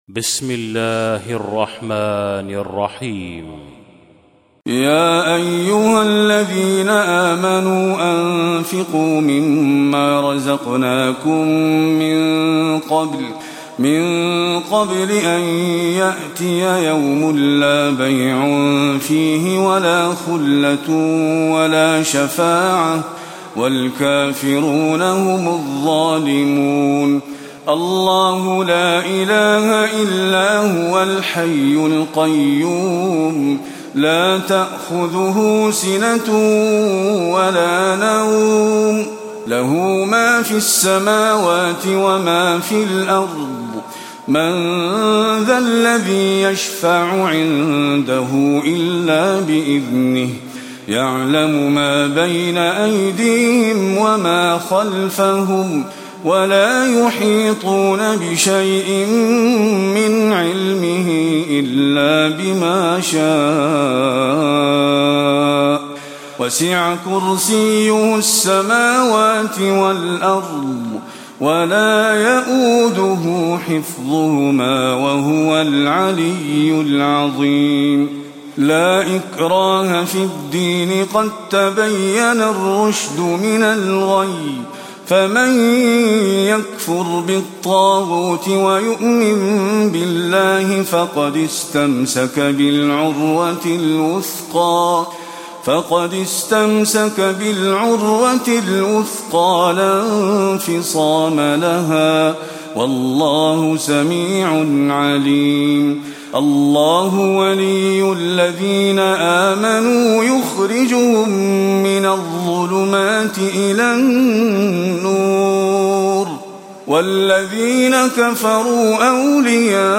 تلاوة سورة البقرة من آية 254 آية أخرها
تاريخ النشر ١ محرم ١٤٣٧ هـ المكان: المسجد النبوي الشيخ: فضيلة الشيخ محمد خليل القارئ فضيلة الشيخ محمد خليل القارئ سورة البقرة (254-أخرها)